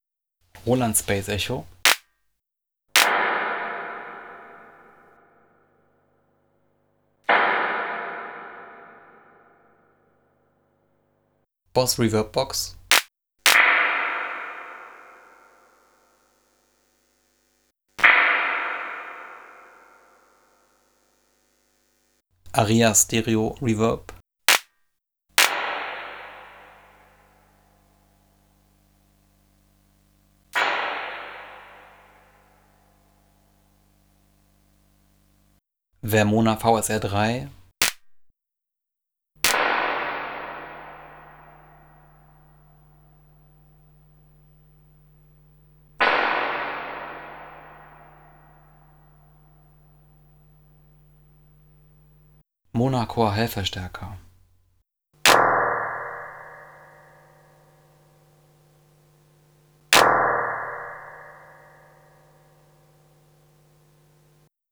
Spring Reverb Test
Aria AR525 - ein echter Stereo Hall mit 2 Spiralen, 19" Vermona VSR3, 19" Monacor Hallverstärker man hört zunächst die trockene Clap aus dem Drumsynth Vermona DRM1 MKIII. dann Clap+Reverb und dann nur das 100% wet Signal des Reverbs, falls möglich.